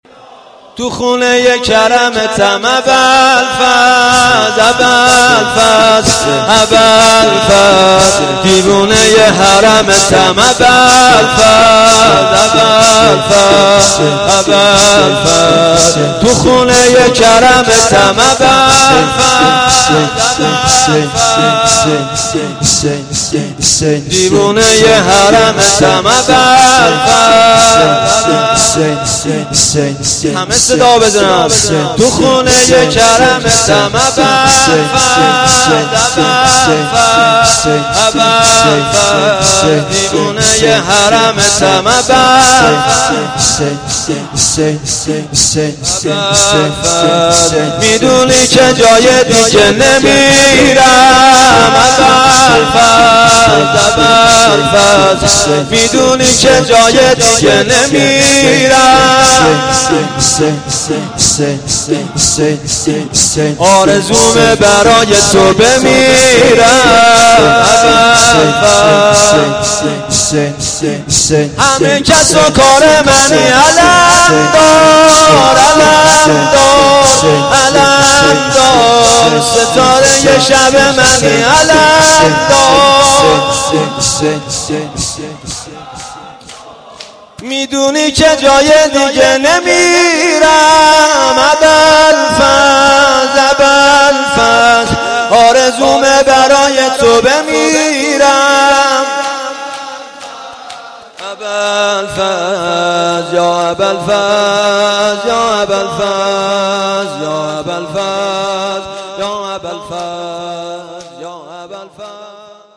مداح